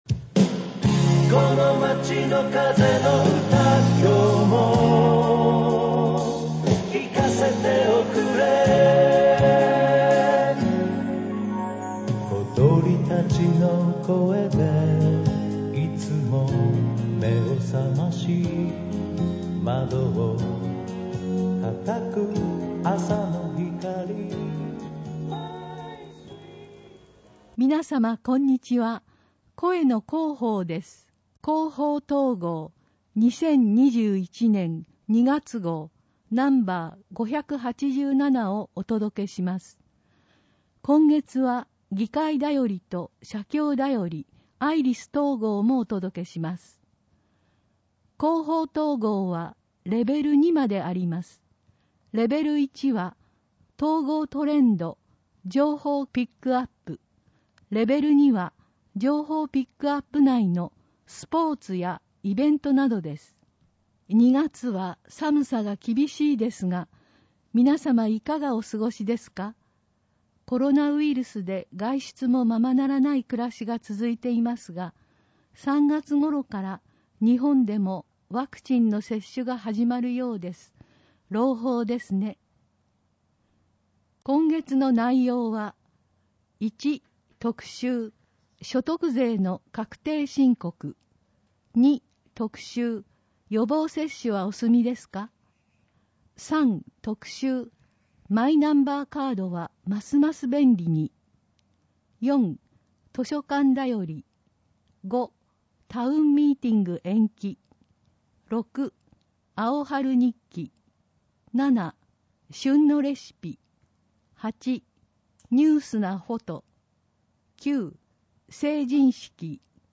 広報とうごう音訳版（2021年2月号）